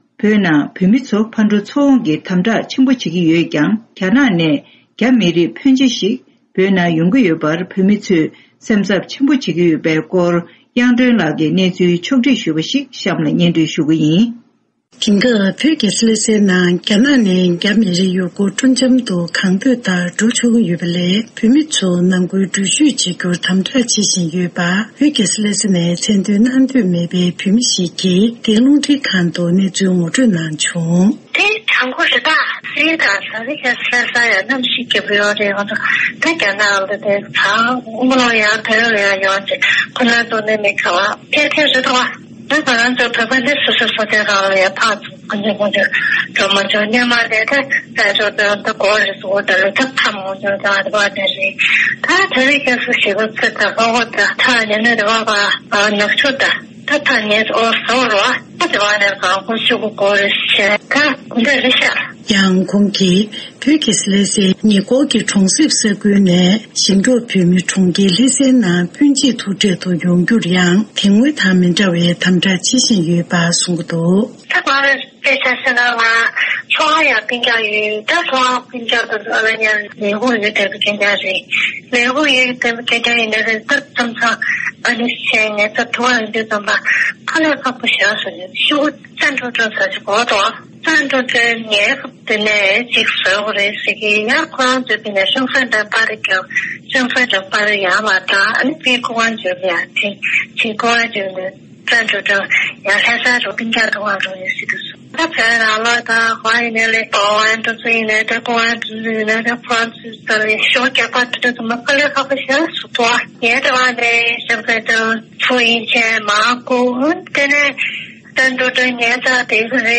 བོད་ཀྱི་རྒྱལ་ས་ལྷ་སའི་མི་སྣར་གནས་འདྲི་ཞུས་སྟེ་གནས་ཚུལ་ཕྱོགས་སྒྲིག་ཞུས་པ་ཞིག་གསན་རོགས་གནང་།།